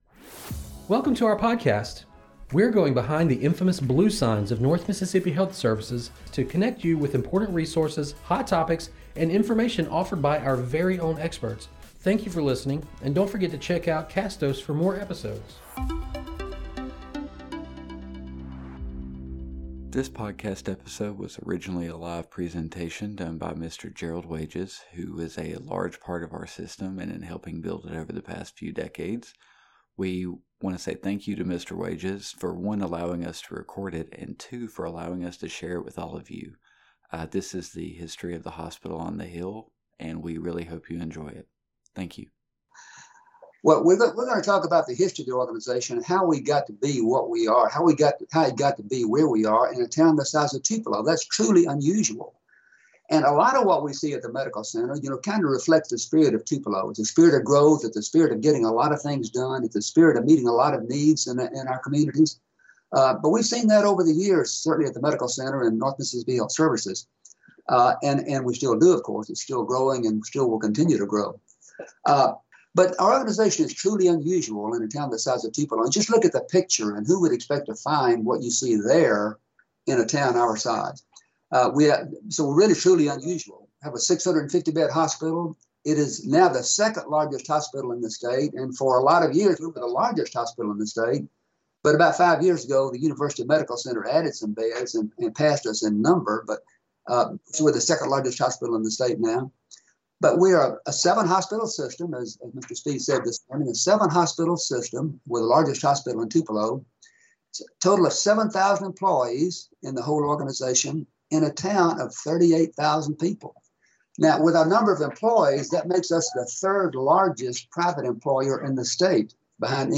He begins with the incorporation of Tupelo, and works his way towards today! Recorded as a live presentation, and converted to audio format for this podcast.